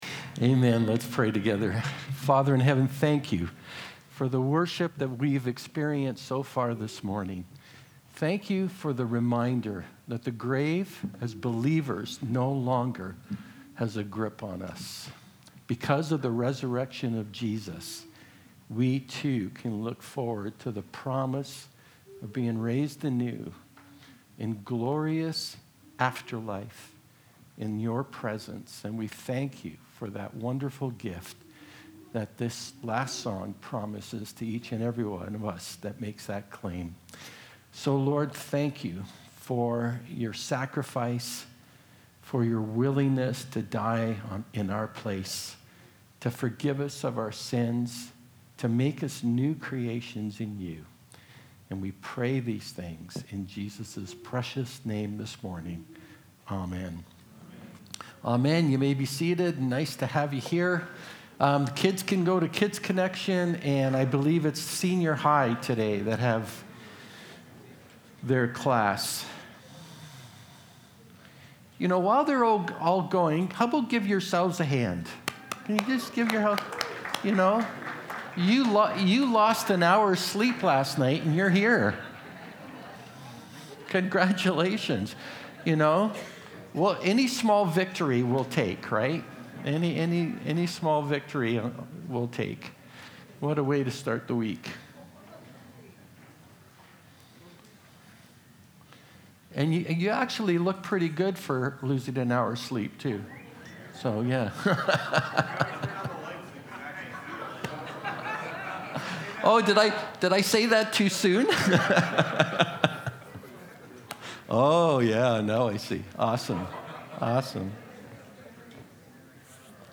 The letters of the New Testament contain a wealth of theachings and guidance for Christians. This is the first of two messages on the NT letters. 1 Thess. 1:1-9 life group notes powerpoint